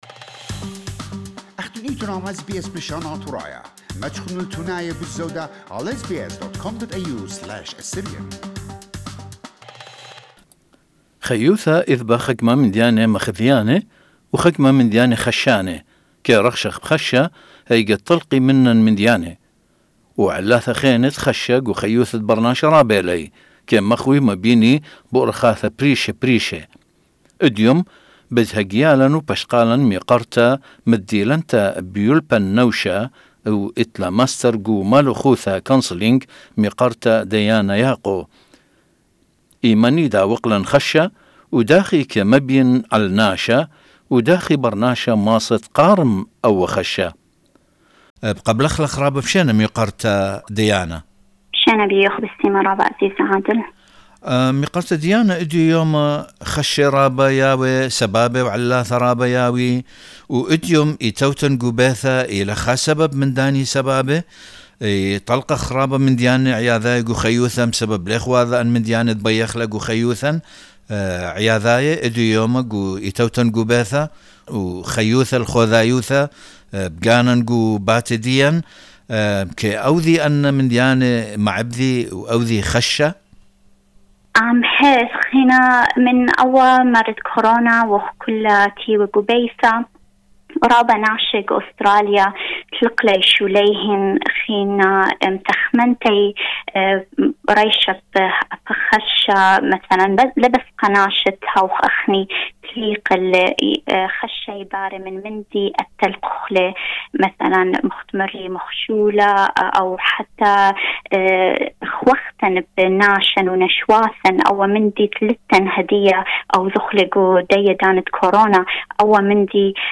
In an interview with counsellor